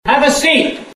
Now you can have the robed man of Keene’s district court, Edward Burke, yell at you every time you get a phone call!